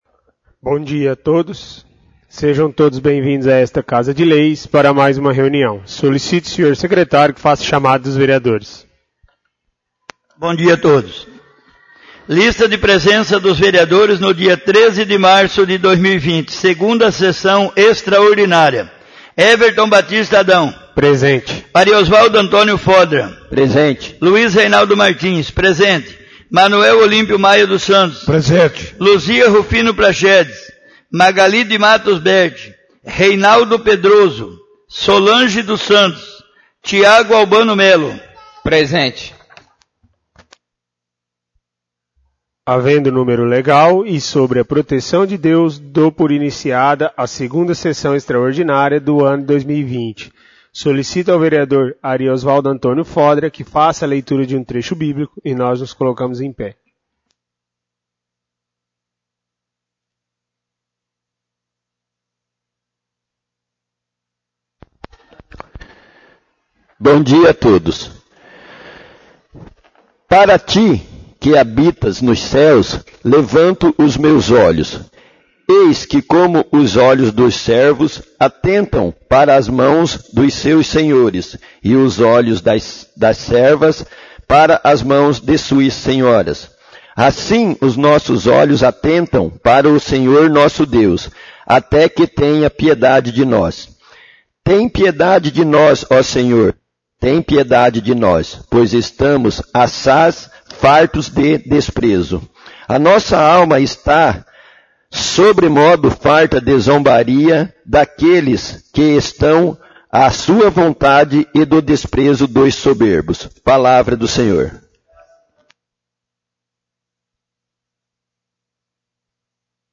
2° Sessão Extraordinária de 2020 — CÂMARA MUNICIPAL